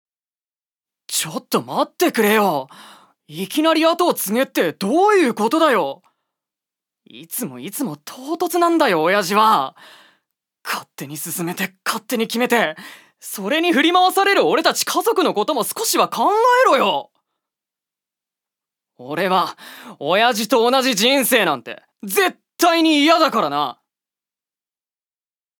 所属：男性タレント
セリフ６